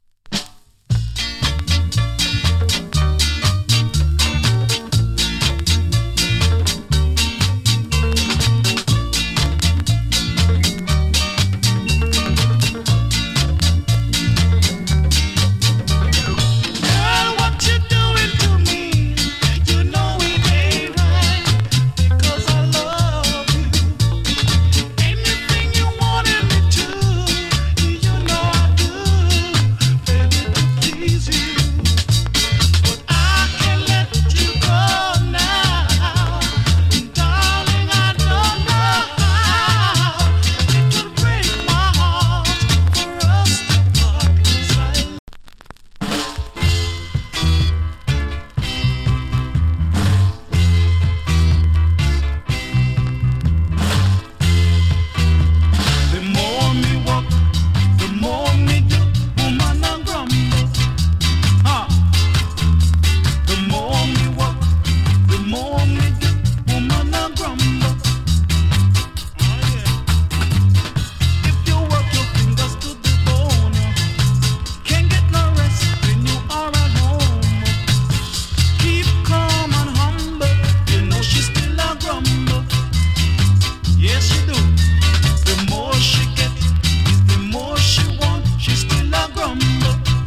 69年 NICE VOCAL EARLY REGGAE !